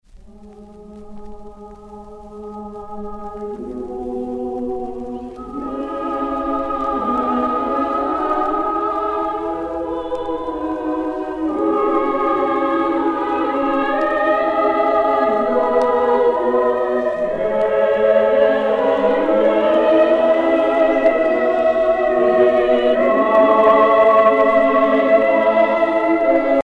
prière, cantique
Pièce musicale éditée